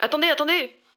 VO_ALL_Interjection_03.ogg